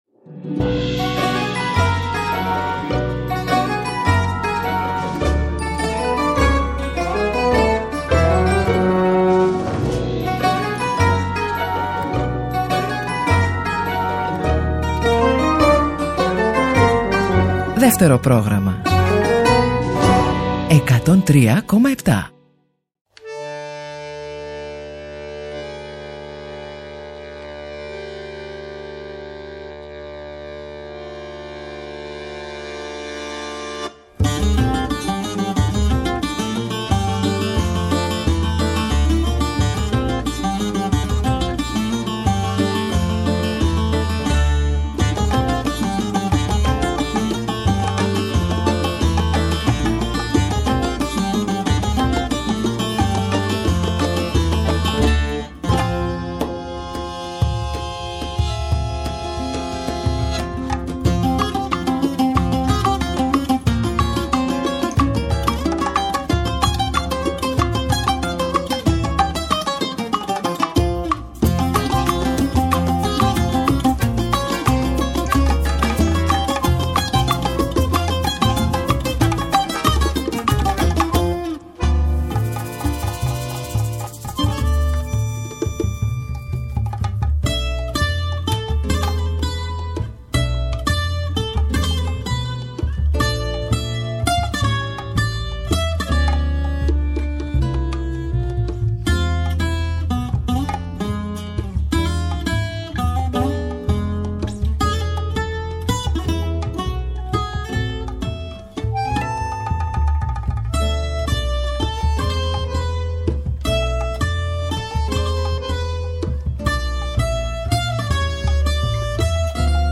εκλεκτός καλεσμένος στο στούντιο του Δεύτερου Προγράμματος
Συνεντεύξεις